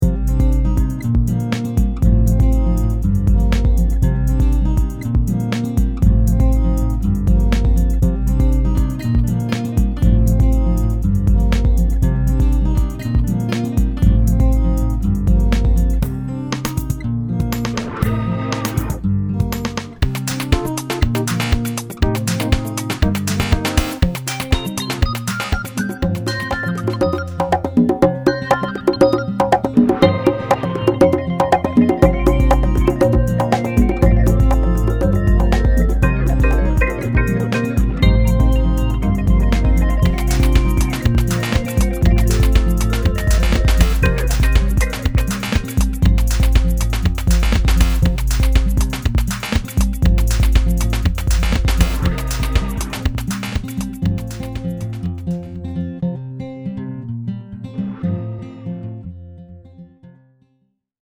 /sketches/
electronic loops